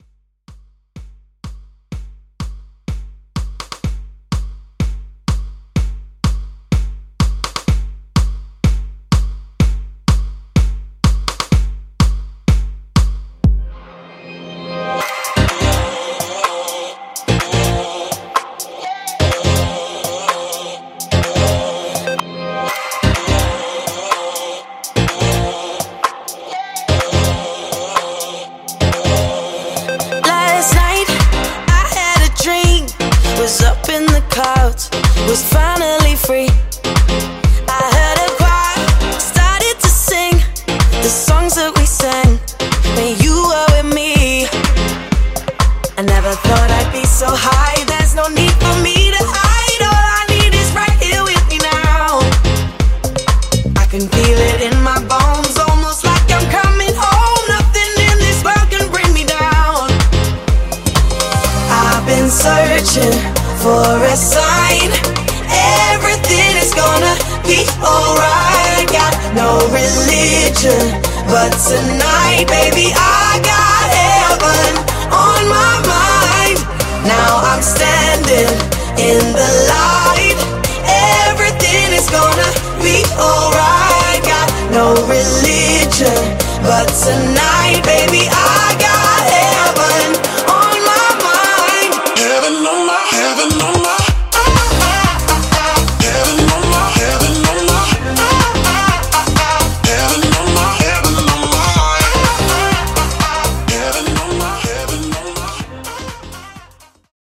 Pop Re-Drum)Date Added